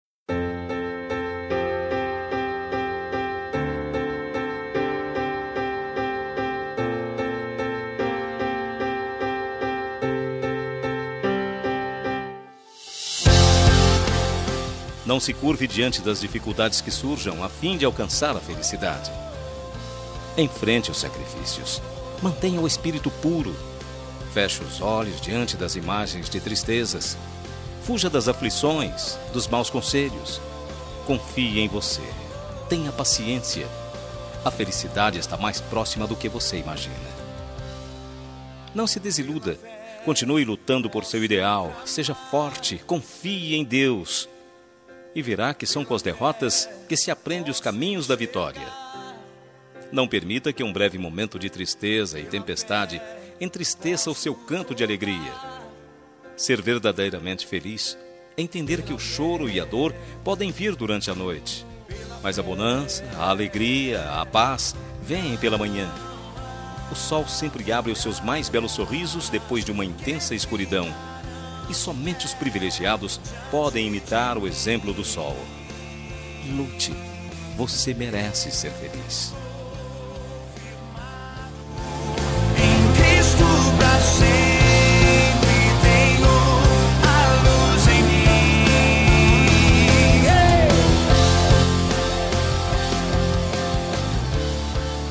Telemensagem de Otimismo – Voz Masculina – Cód: 8079